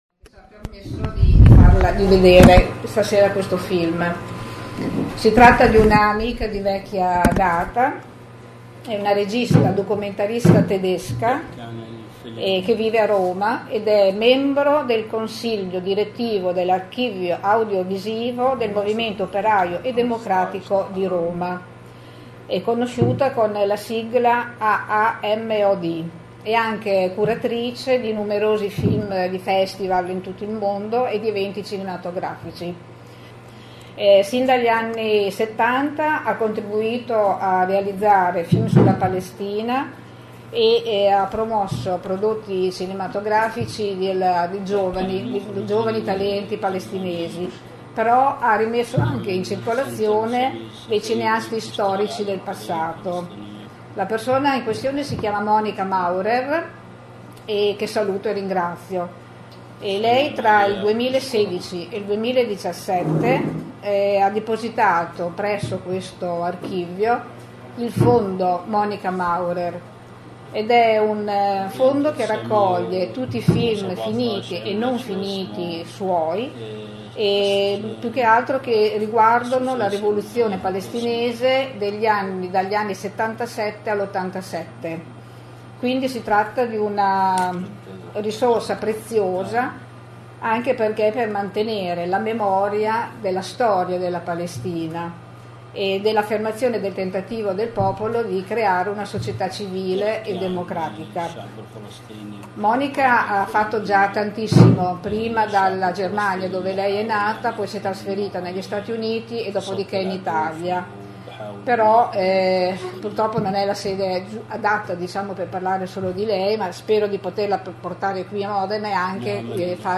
A Modena proiettato e discusso Broken, il film sul muro dell’apartheid e la violazione israeliana della Corte Internazionale di Giustizia.
e il pubblico presente in sala .